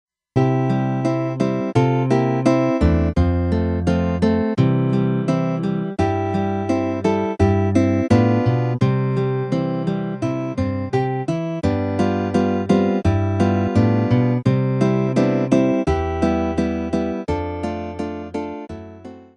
ひと昔前の、ピコピコしたいかにもコンピュータ音といったものに比べて、ずいぶん現実の楽器の音に近づいてきました。
Microsoft GS Wavetable SW Synth